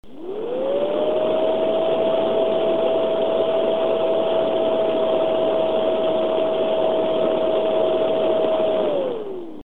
Thermal Integration Thermal Test Results, Acoustic Sample - TI-S8640L FrostyTech Review